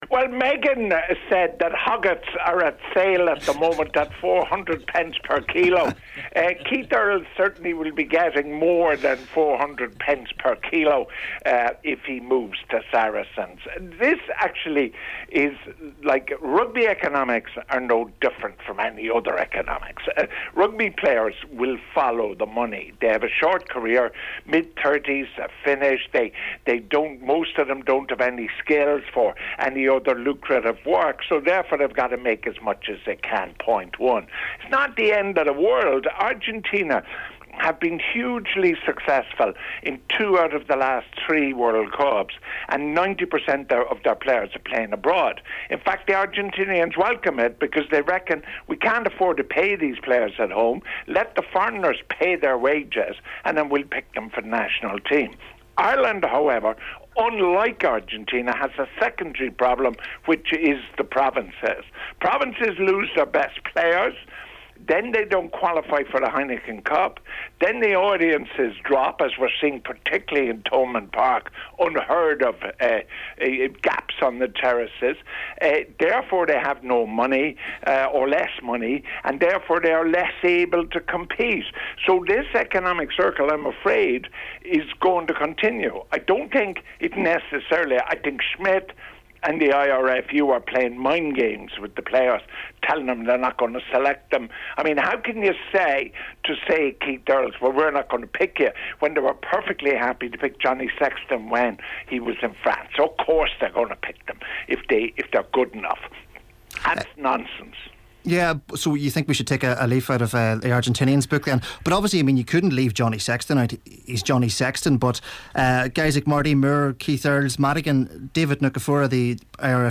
Rugby analyst George Hook on the issue of Irish players moving abroad